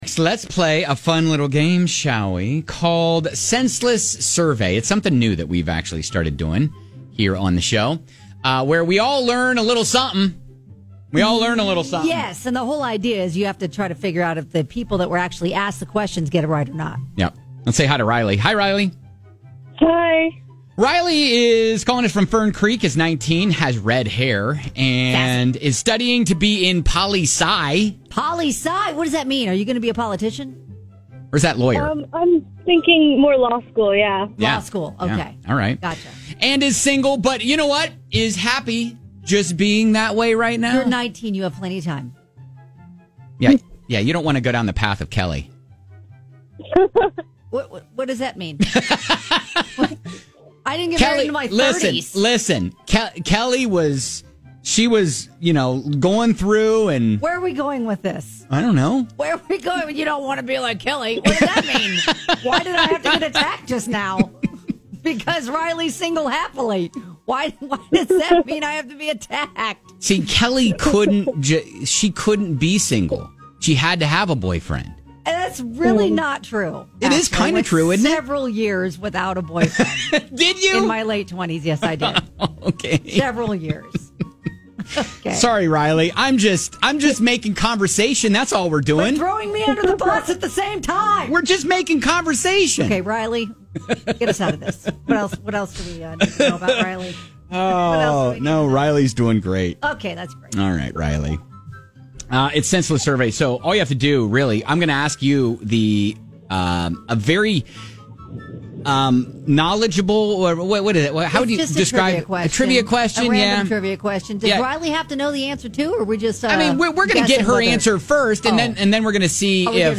All you have to do it guess whether the people being asked these random trivia questions will get it right or not!